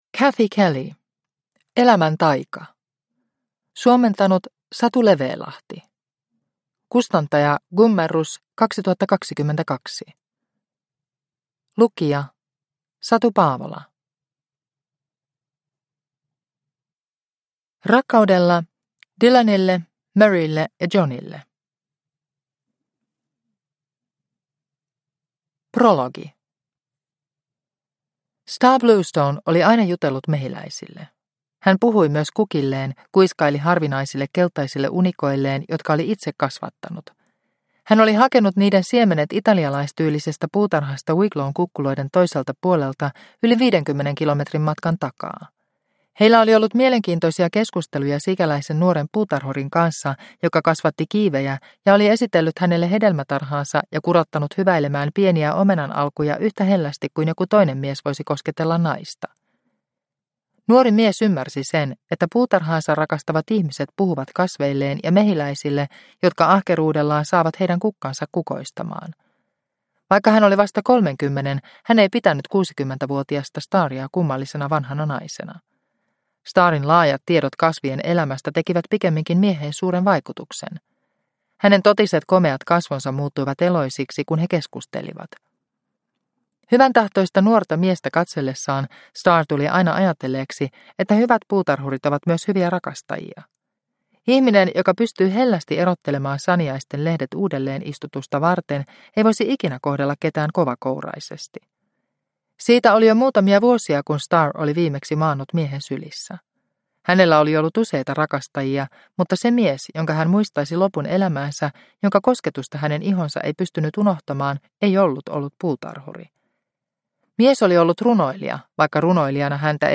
Elämän taika – Ljudbok – Laddas ner